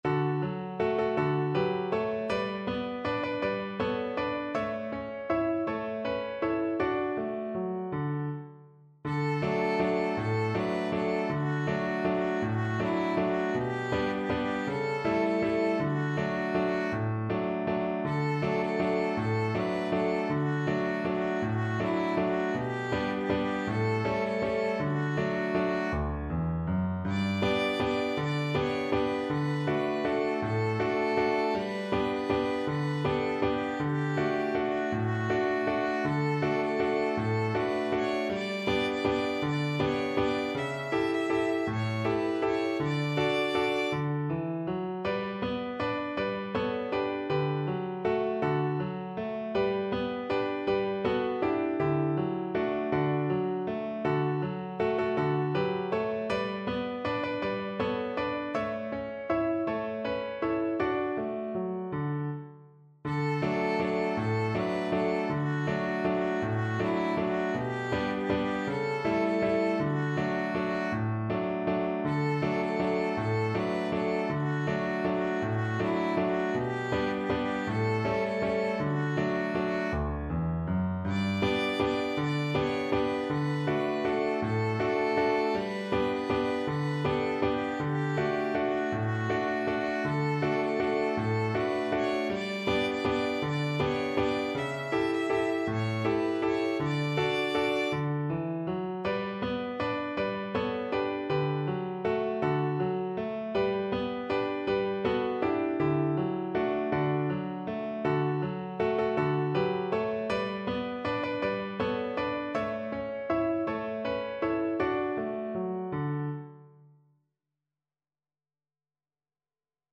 3/4 (View more 3/4 Music)
One in a bar =c.160
Violin  (View more Easy Violin Music)